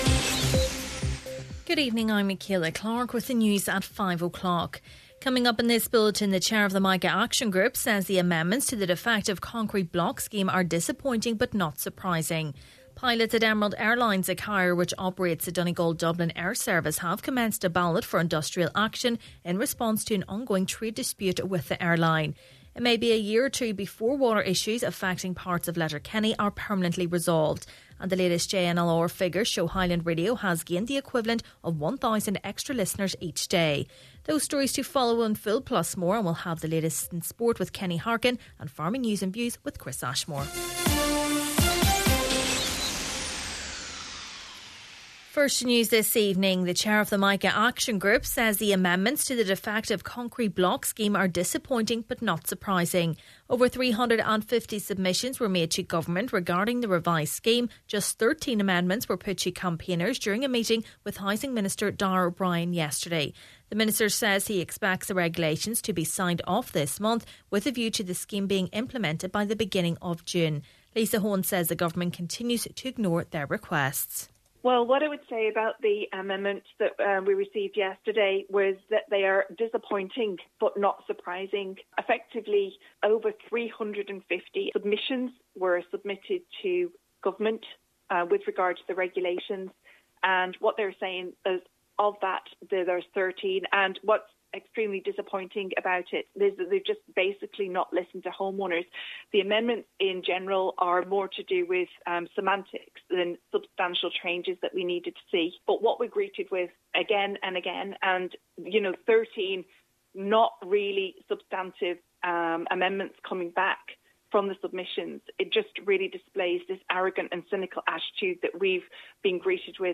Main Evening News, Sport, Farming News and Obituaries – Thursday May 11th